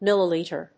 音節mil･li･li･ter (())mil･li･li･tre発音記号・読み方mɪ́ləlìːtər
• / ˈmɪlʌˌlitɝ(米国英語)